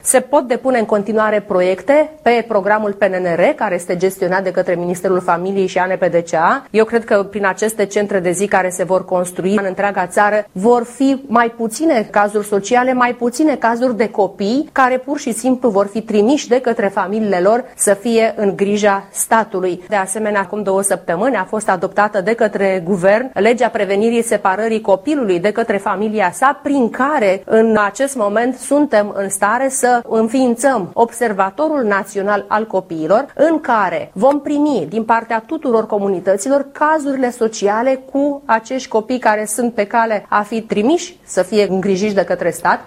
Gabriela Firea, ministrul familiei, tineretului și egalității de șanse.